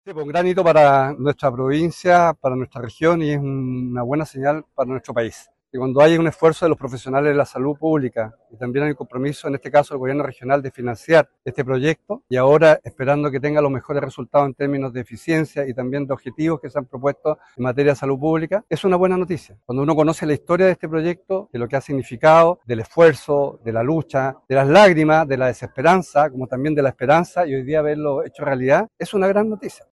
El gobernador regional, Alejandro Santana, calificó la apertura del centro como “un hito para la región y un compromiso con la salud pública”.